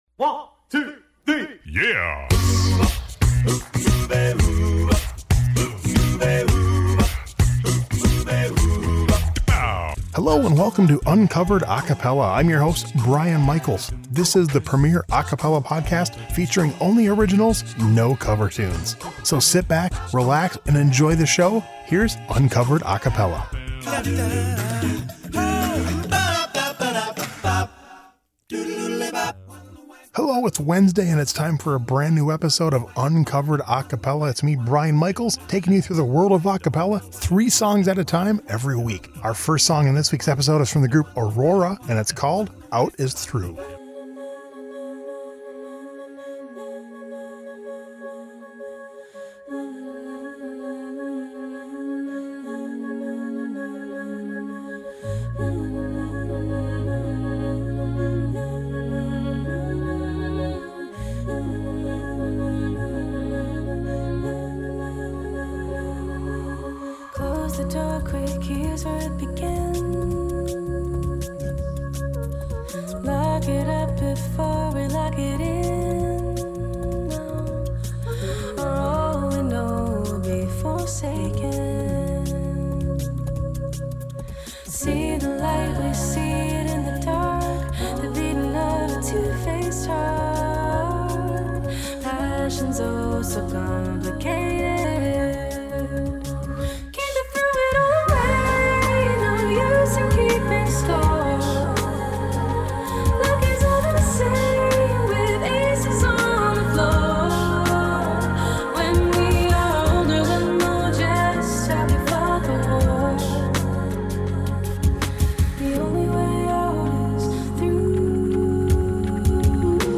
Vocals + Electronics
Upbeat pop/rock a cappella group from Boston
All-Vocal Rock